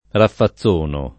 raffazzono [ raffa ZZ1 no ]